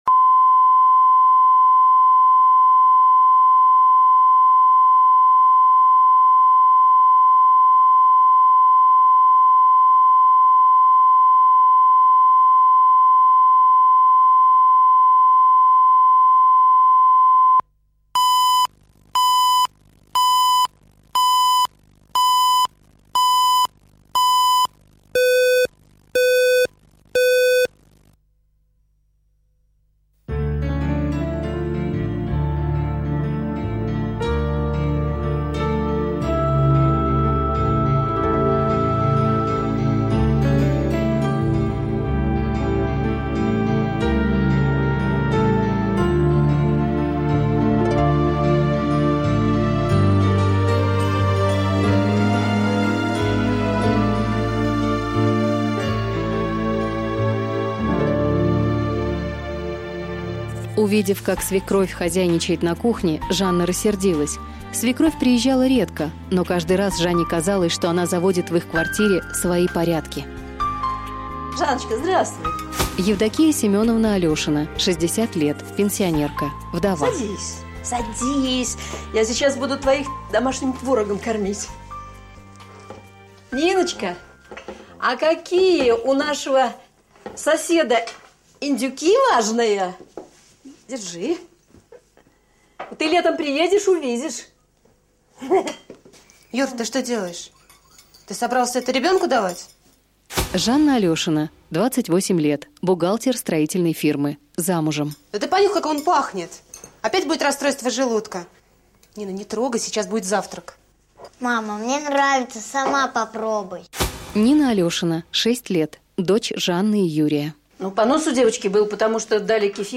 Аудиокнига Городская